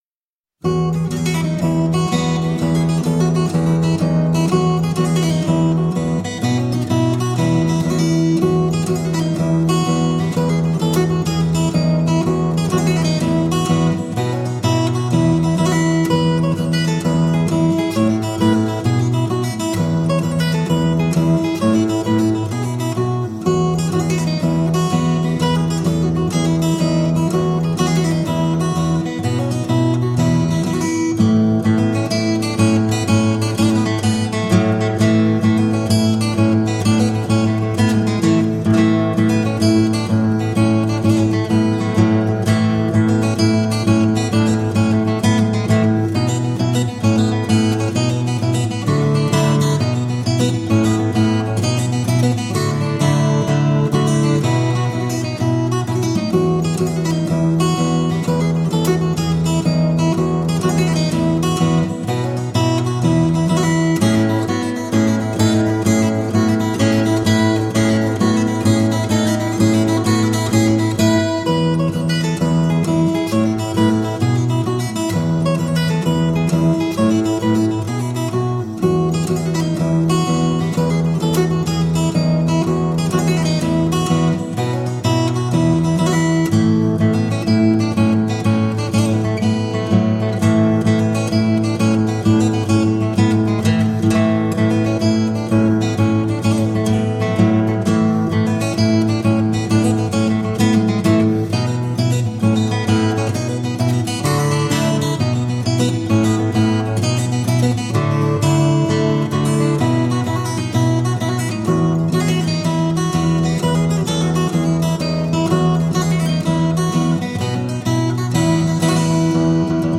Filmic composer and acoustic fingerstyle guitarist.
solo acoustic guitar